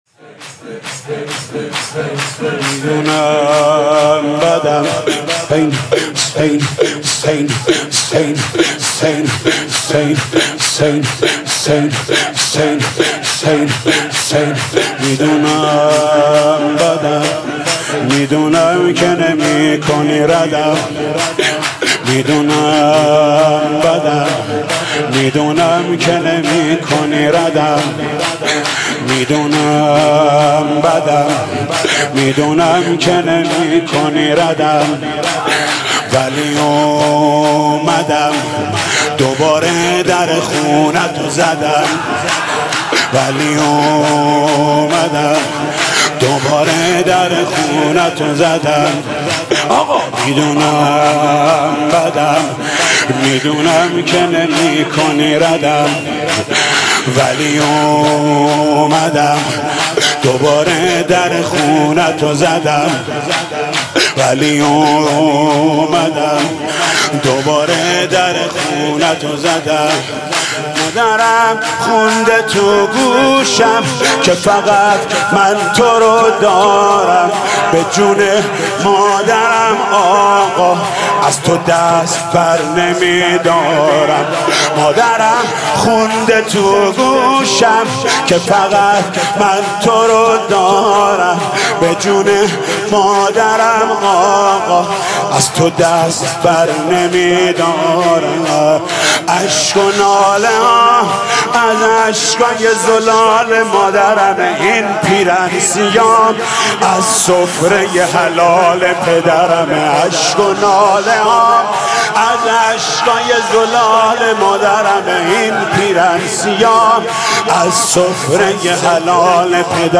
فایل‌های صوتی مداحی و روضه‌خوانی شب چهارم محرم 94 با نوای «حاج محمود کریمی» و «حاج عبدالرضا هلالی» آماده دریافت است.
به گزارش پایگاه 598، فایل‌های صوتی مداحی و روضه‌خوانی  شب چهارم محرم الحرام 1437 در هیئت رایت العباس چیذر با نوای حاج محمود کریمی و حاج عبدالرضا هلالی آماده دریافت است.